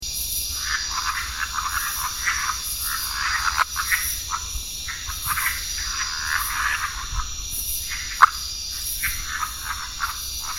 The breeding call is a 'wor-or-op' repeated every one to several minutes.